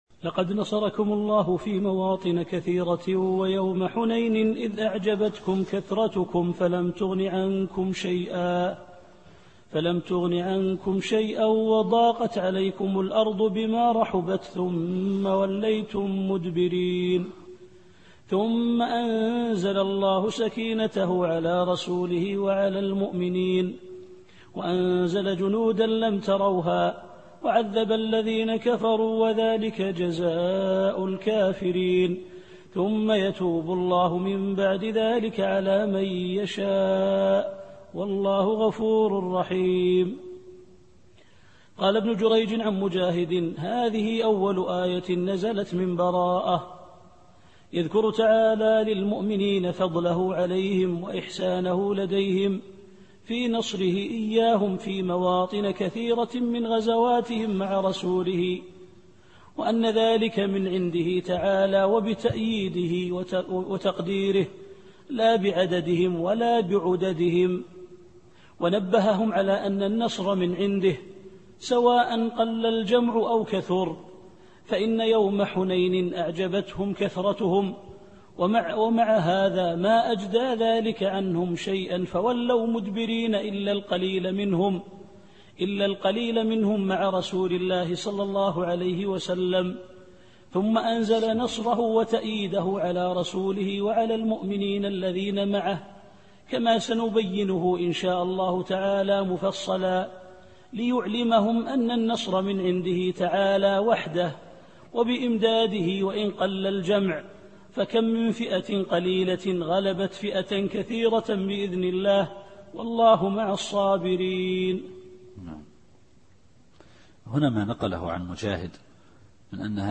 التفسير الصوتي [التوبة / 26]